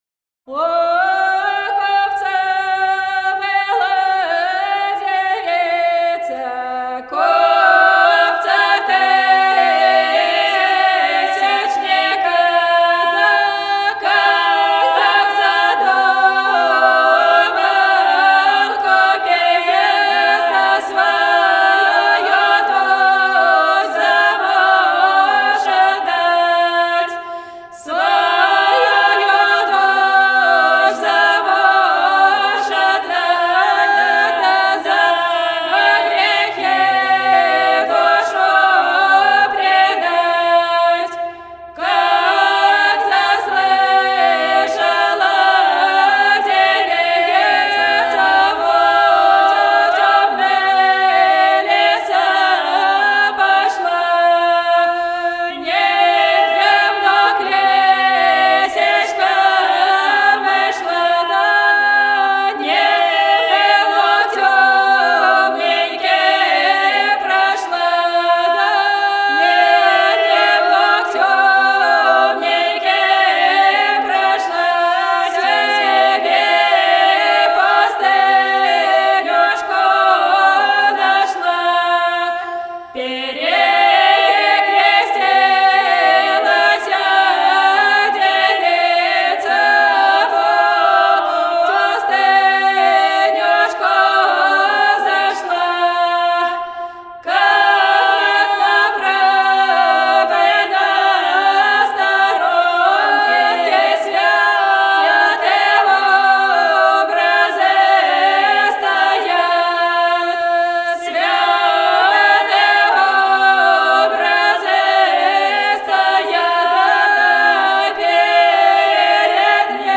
Духовными стихами называют народные песнопения на религиозные сюжеты.
Стих «
У купца была девица» (запад России)